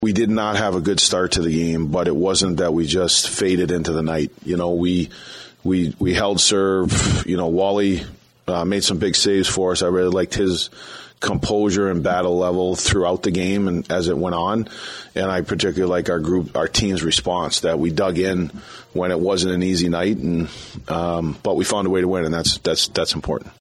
Wild head coach